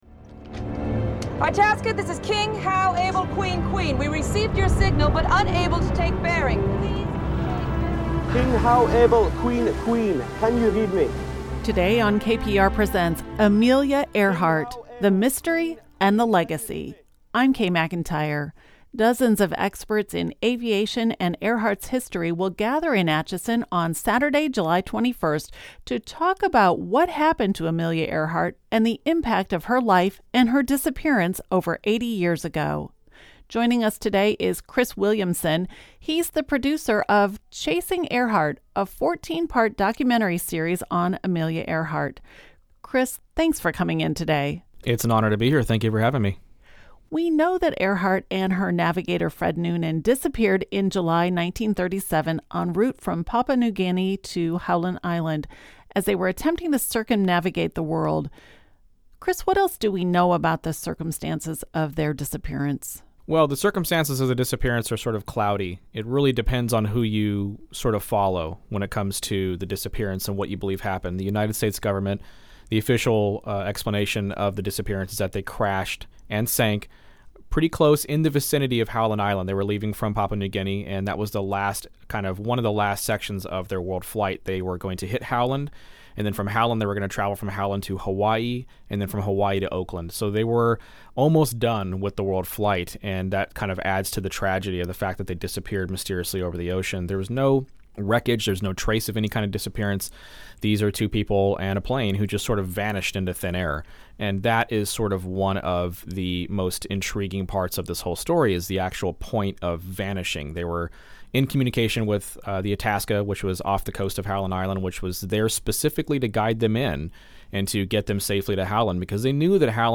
KPR Presents: A Conversation